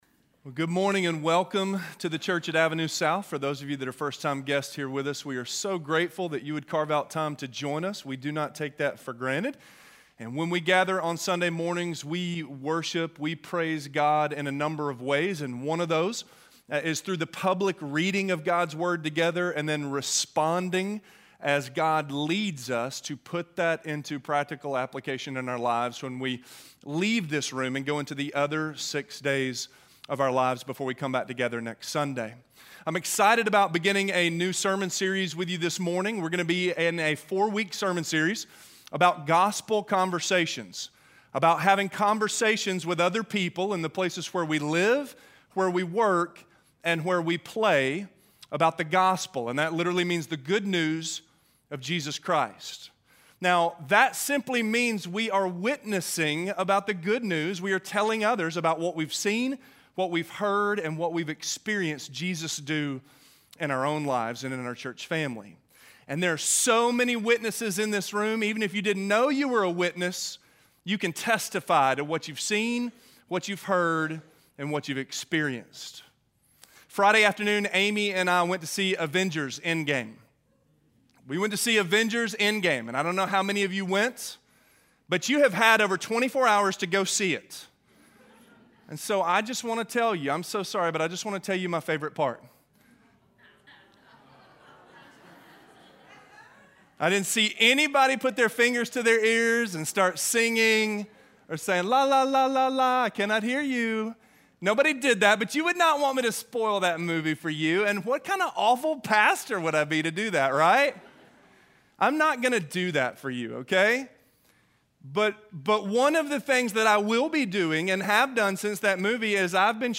God’s Story - Sermon - Avenue South